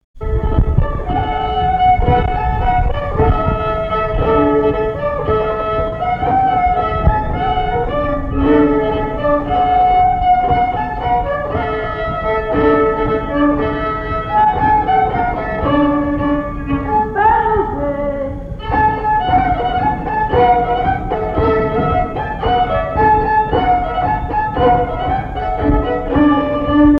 danse : quadrille
Assises du Folklore
Pièce musicale inédite